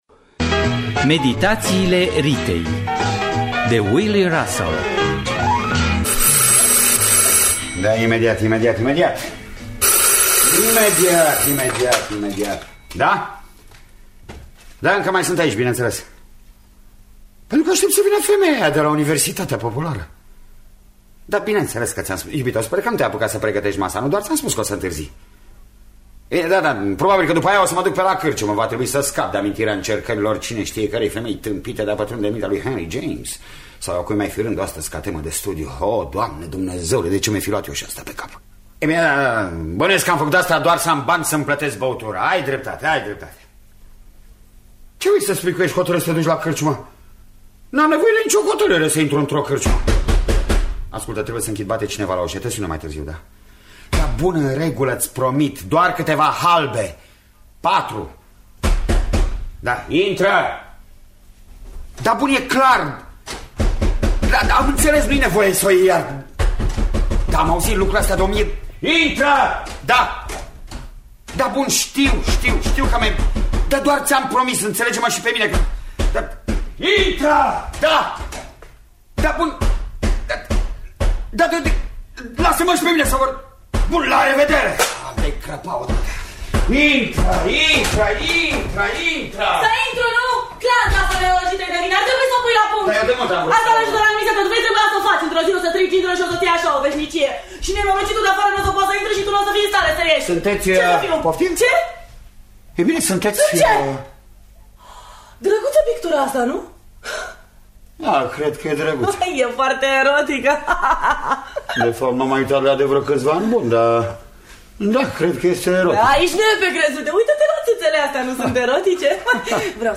În rolurile principale: Emilia Popescu şi Florian Pittiş.